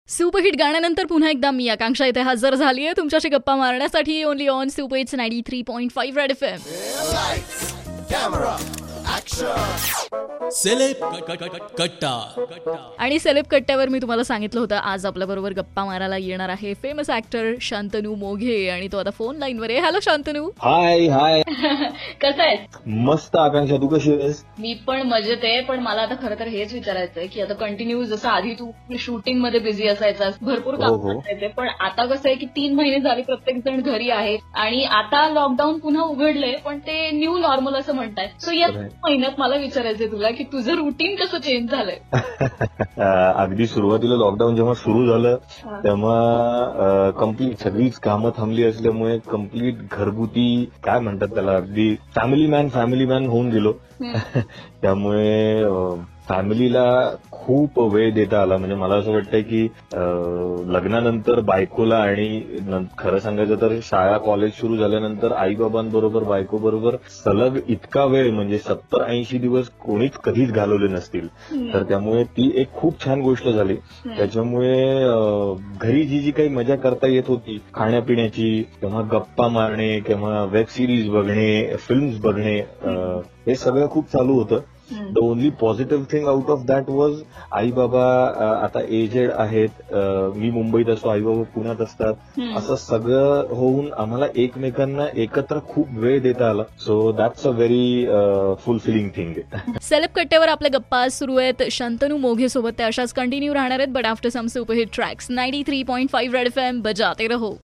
took an interview of famous actor Shantanu Moghe about his lockdown experience.